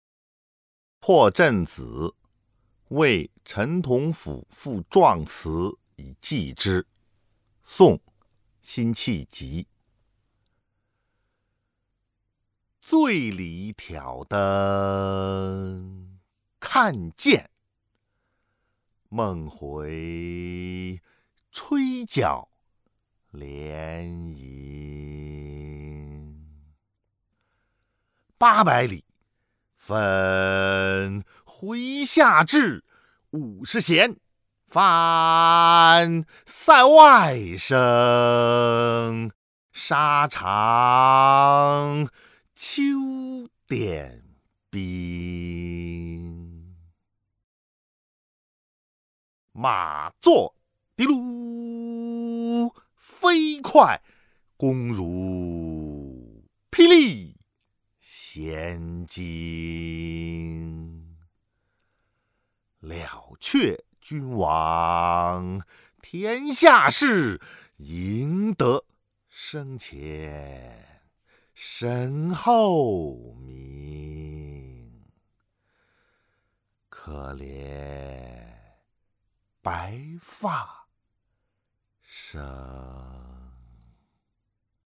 ［宋］辛弃疾 《破阵子·为陈同甫赋壮词以寄之》（读诵）